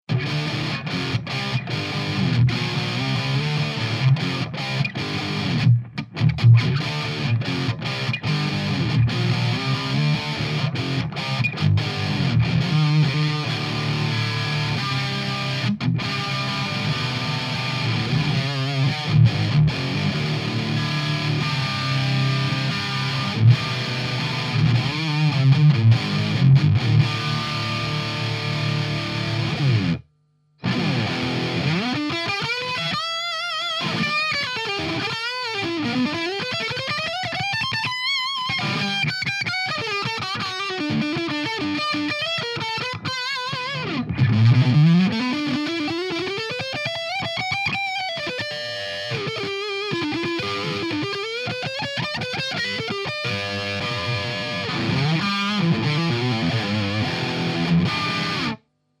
Poslal mi ukazku z vystupu slave + nahravku mikrofonom od bedne pri izbovej hlasitosti.
Prvy je cisty linkovy signal, druhe je mikrofon od bedne. ostatne su impulzy. podla nazvov tych impulzov by malo ist o JCM2000, Plexi a Mesa Boogie nejake: